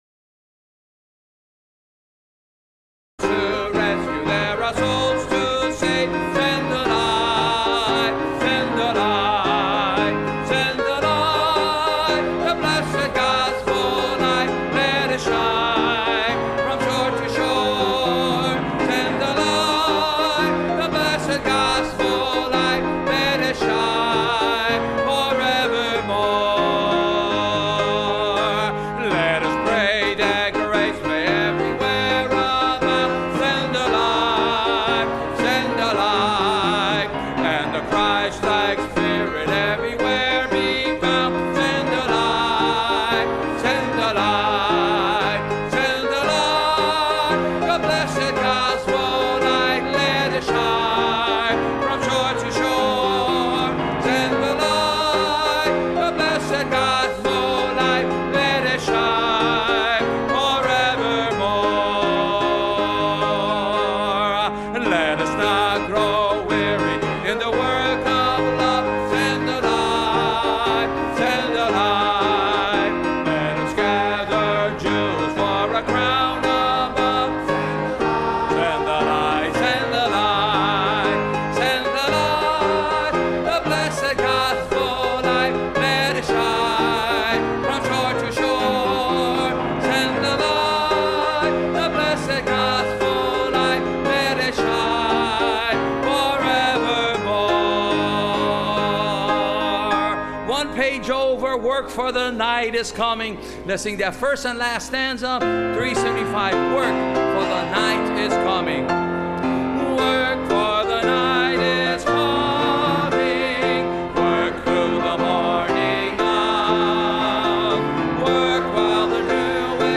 SWM Testimonies – Landmark Baptist Church
Service Type: Wednesday College